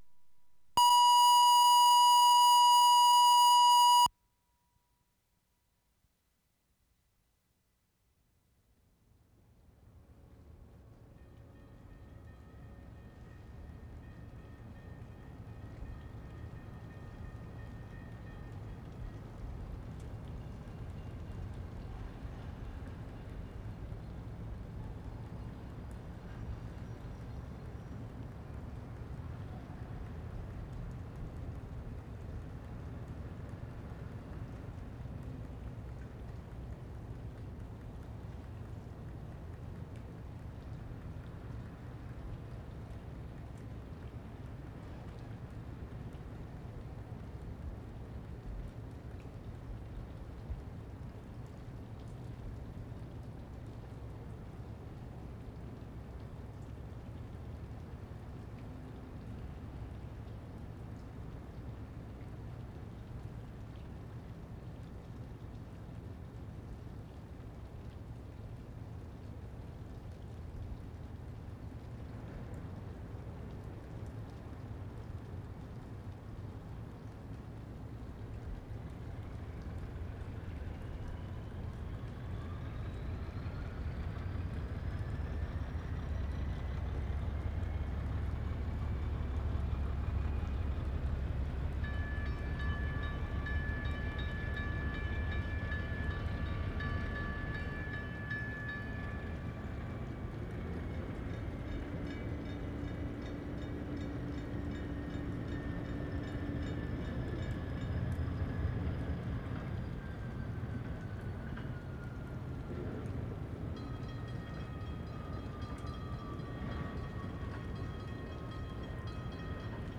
CN PIER ON MAIN STREET March 12, 1973
TRAINS 9'00"
1. This is a low-key, but rich sequence. Patterns of train movement mix with rain, and the explosion of the 9 o'clock gun in a complex series of changes and events. Signal patterns of whistles and bells appear in continually changing spatial perspective. The recurring movement of the yard engine contrasts with its more fixed pattern of back and forth motion. The rain appears to lighten over the sequence.
1'15" diesel winds up, crosses road (bell sounding), long glissando to engine.
2'10" winds up again, followed by glissando. Rain in foreground. Sequence repeats several times throughout.
*3'35" 9 o'clock gun, with multiple echo.
*4'47" train whistle, more distant than the engine in foreground, repeated several more times, receding into distance.
7'45" distant train crossing bells.
8'00" nearer train crossing bells, train whistle still in distance.
8'10" nearest train crossing bells.
8'30" second train whistle, very distant at end.